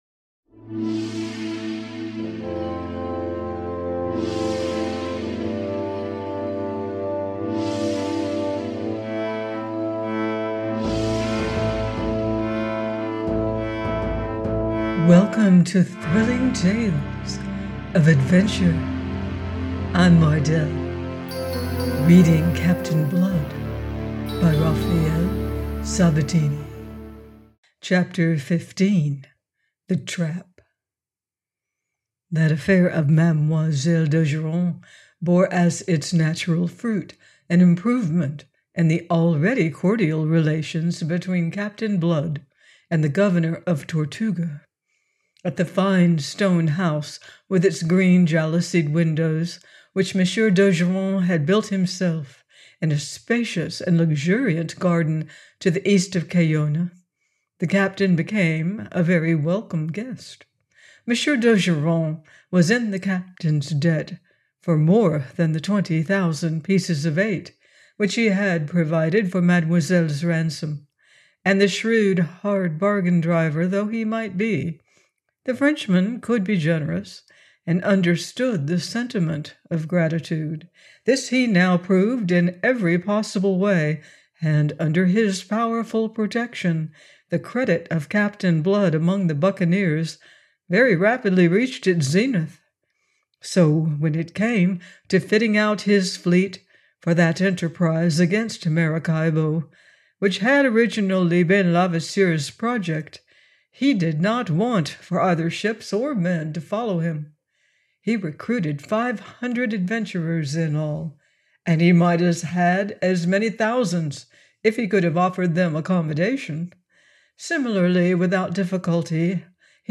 Captain Blood – by Raphael Sabatini - audiobook